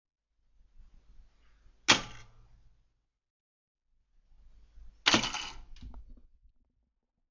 Toaster
Diese Sounds erklingen, wenn der Toaster bald fertig und fertig ist.
toaster